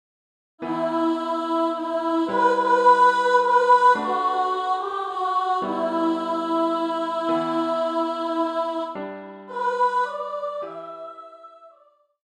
für Gesang, hohe Stimme